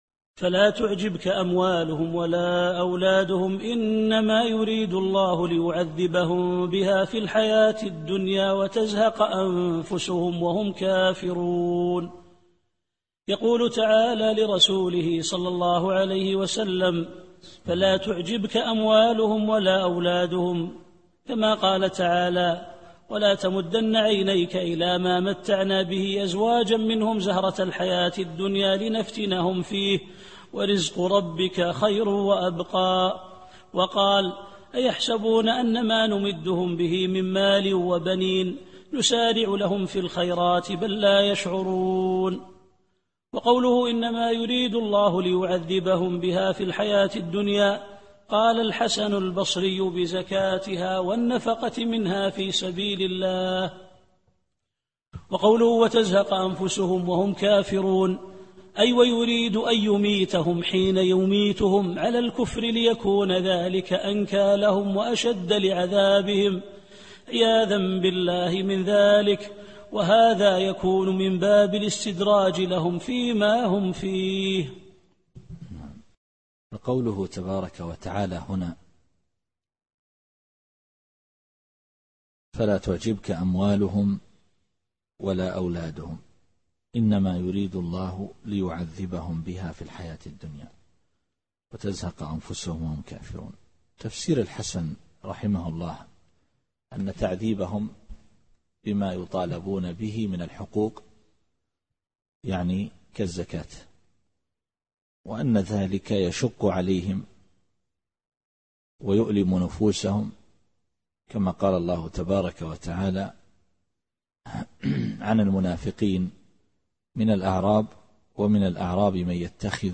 التفسير الصوتي [التوبة / 55]